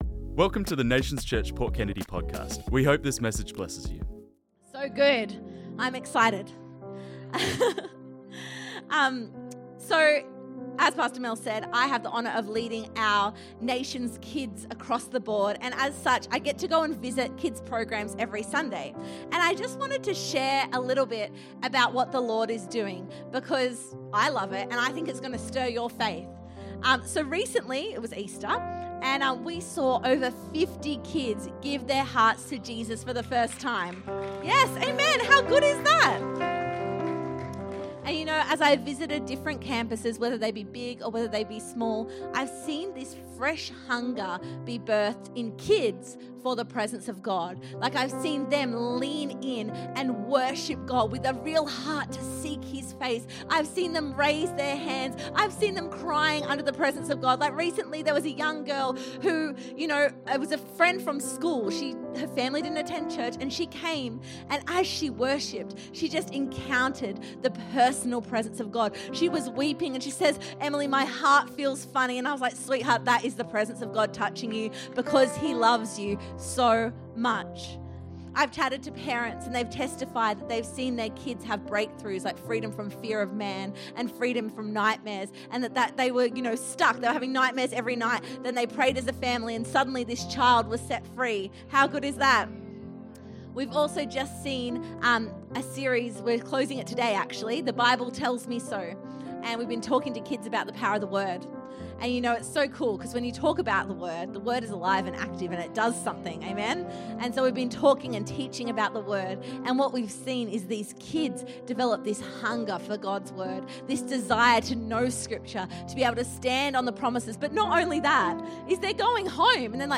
This messaged was preached on Sunday 1st June 2025.